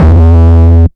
Accent Upright Bass.wav